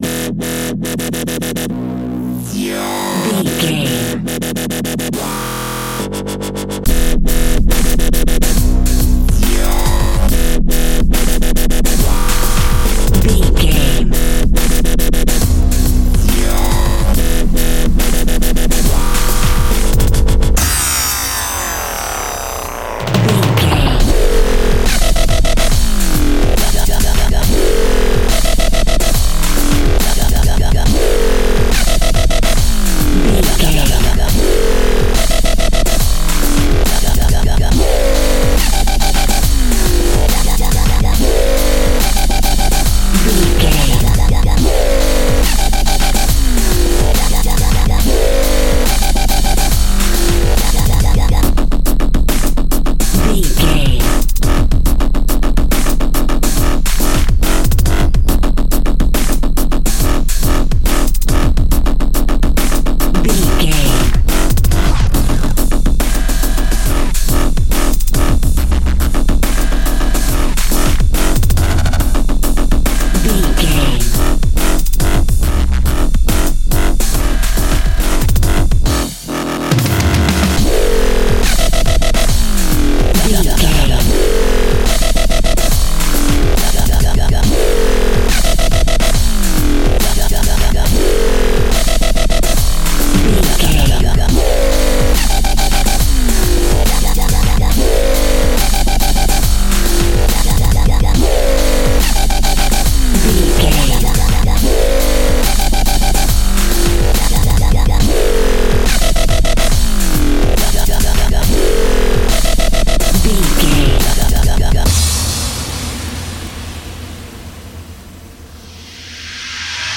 Aeolian/Minor
Fast
aggressive
powerful
dark
driving
futuristic
hypnotic
industrial
mechanical
drum machine
synthesiser
breakbeat
energetic
synth drums
synth leads
synth bass